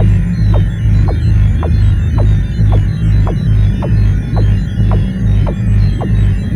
alien_engine_loop_01.ogg